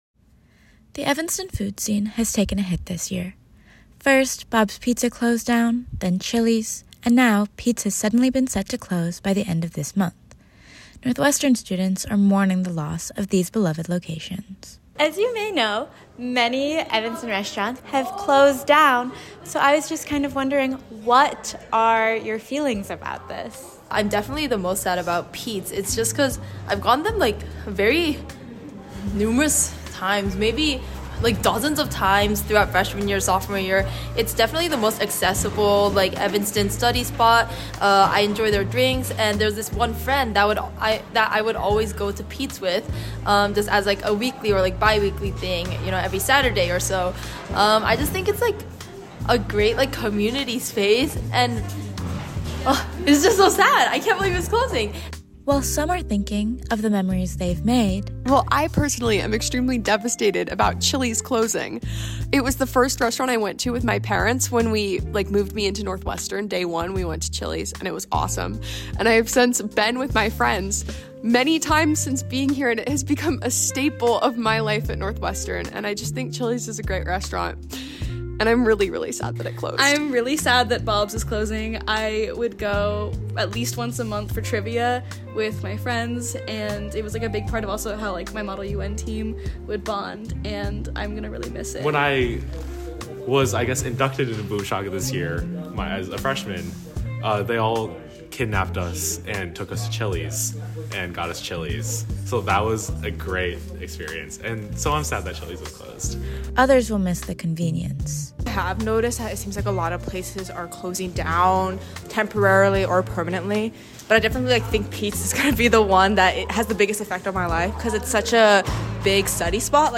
Students react to Evanston restaurant closures